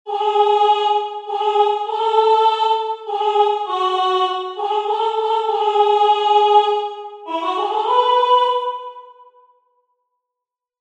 Three-pulse measure—Doh is E.